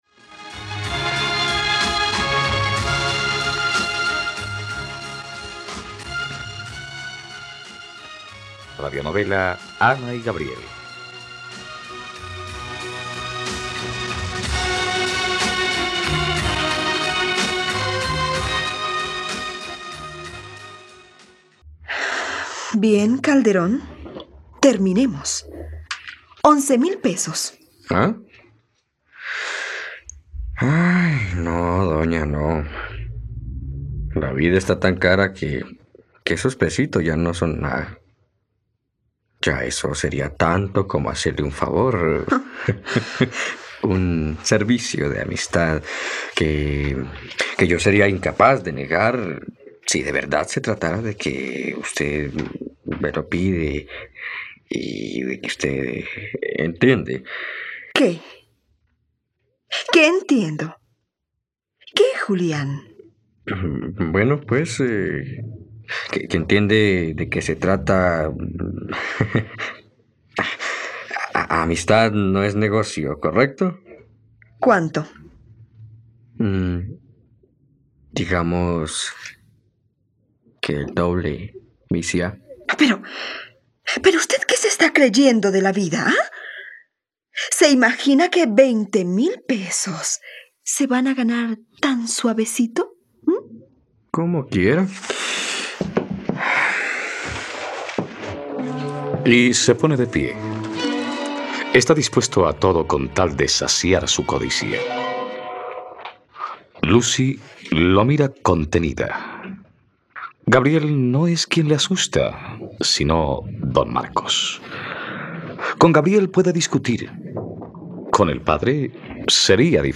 ..Radionovela. Escucha ahora el capítulo 80 de la historia de amor de Ana y Gabriel en la plataforma de streaming de los colombianos: RTVCPlay.